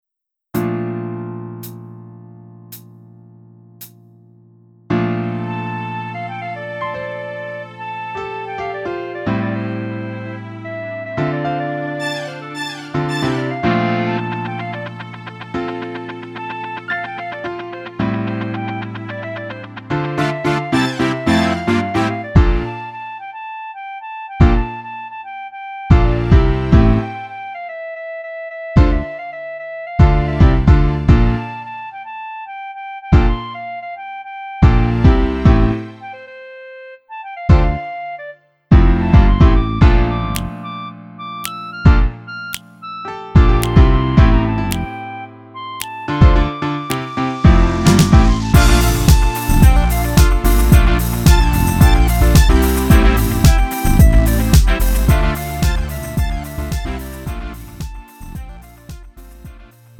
음정 원키 2:51
장르 구분 Lite MR